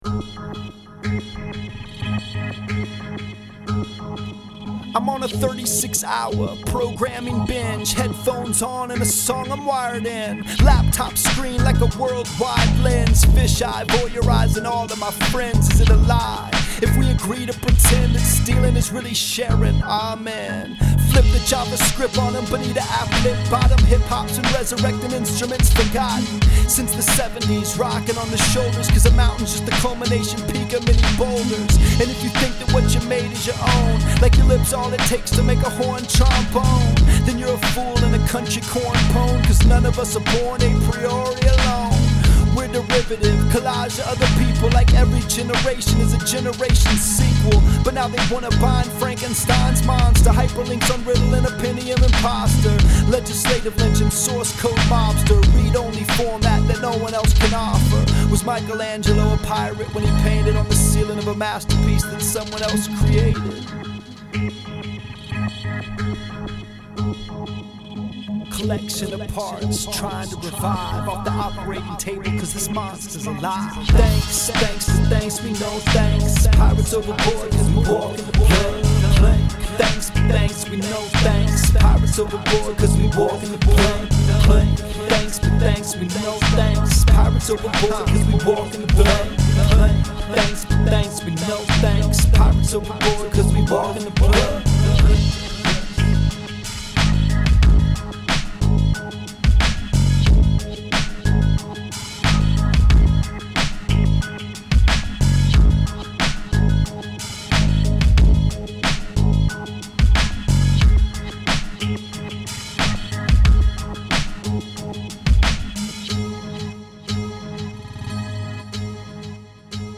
the beat for today is a remix
Today’s song blog here: